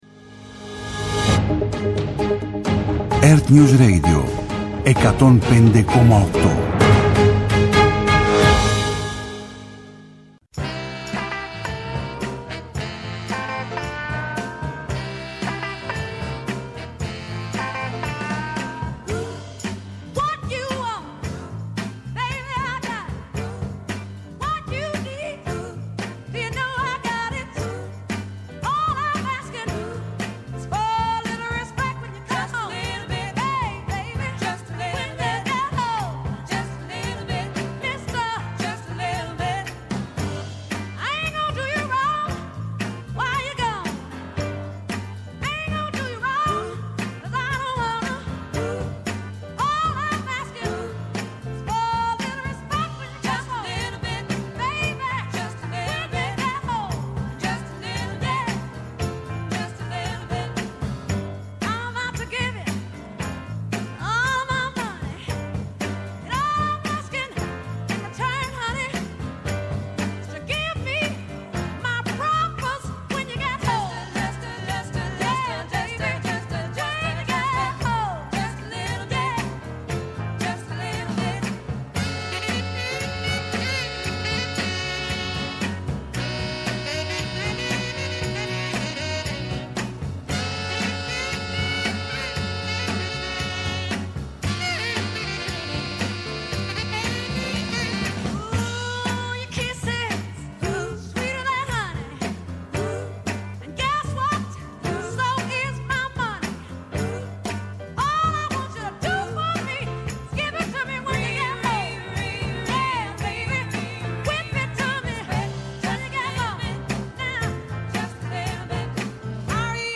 Με αναλύσεις, πρακτικές συμβουλές και συνεντεύξεις με πρωτοπόρους στην τεχνολογία και τη δημιουργικότητα, τα «Ψηφιακά Σάββατα» σας προετοιμάζει για το επόμενο update.